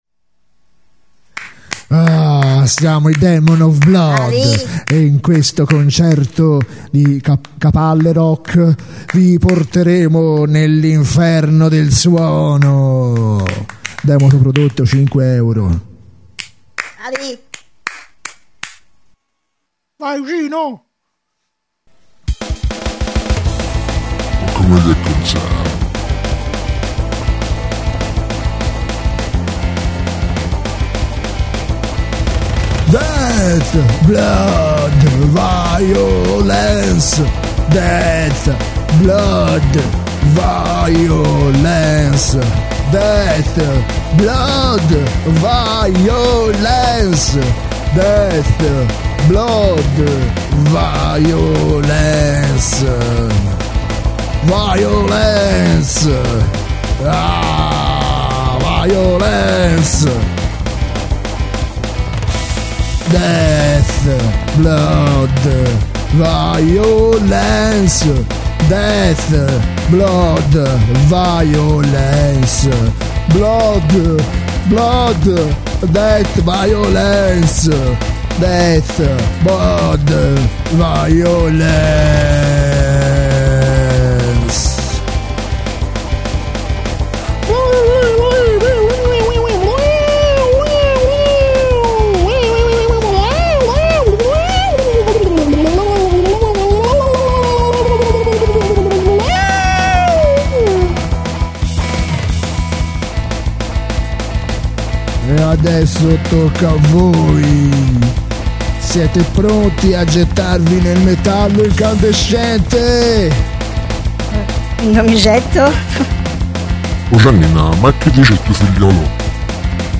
Molto trash!